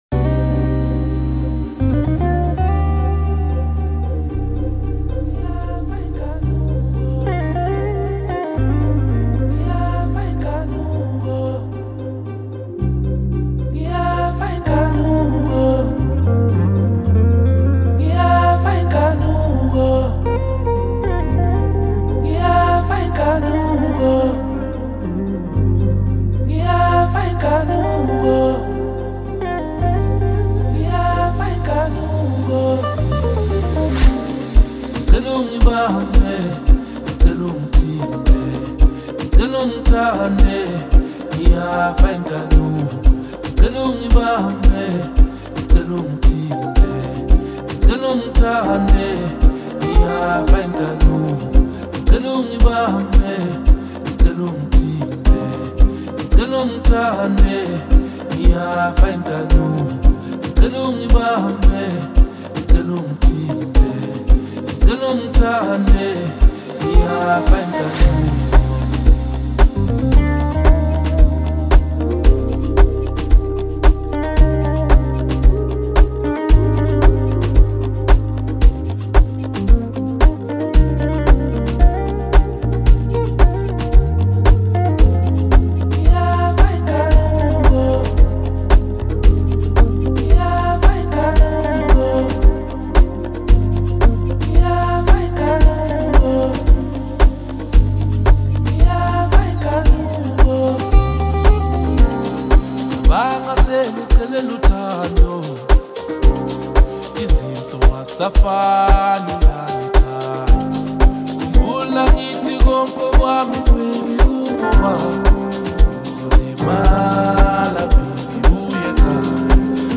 Amapiano, Lekompo